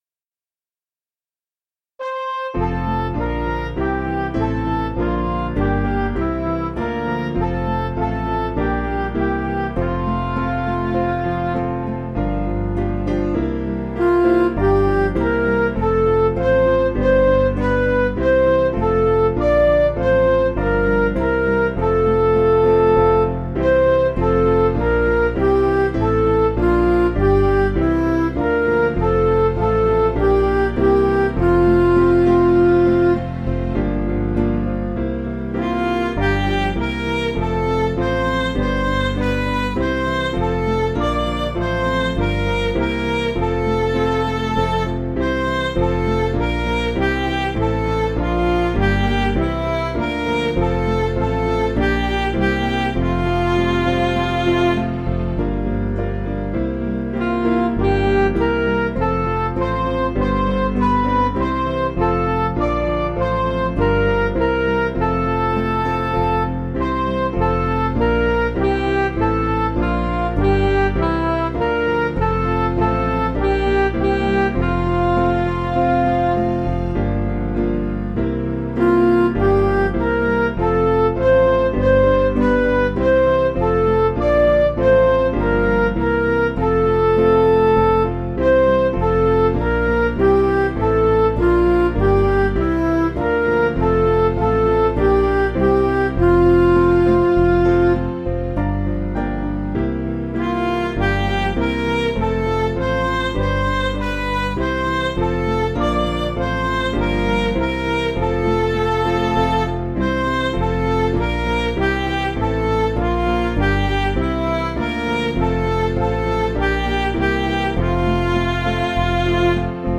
Piano & Instrumental
Midi